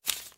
PaperGrab02.wav